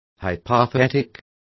Complete with pronunciation of the translation of hypothetic.